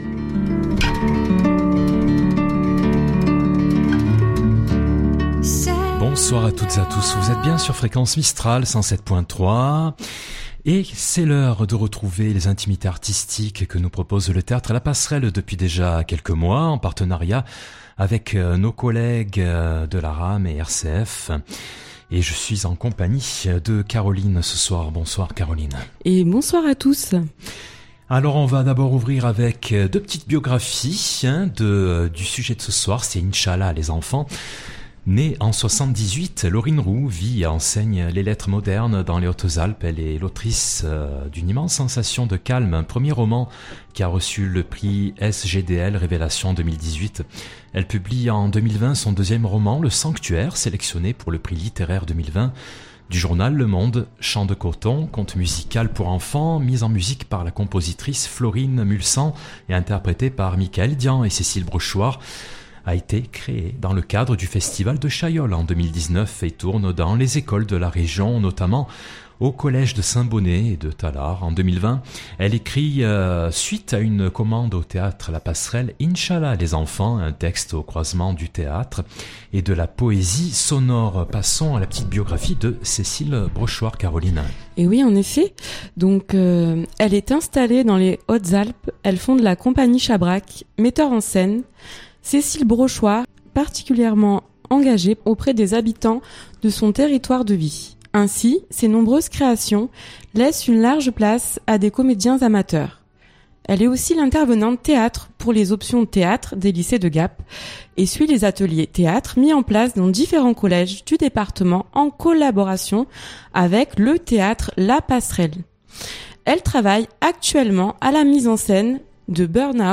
Pour cette diffusion radiophonique vous allez entendre la bande son du court-métrage, un poème sonore en vers libres qui vous invite à suivre dans des lieux désaffectés une mère et ses ti-culs, brassée d’enfants sauvés de la catastrophe qui la suivent en grappe. Dans ce monde effondré, l’amour - avec les paillettes - est peut-être la dernière chose qui résiste encore.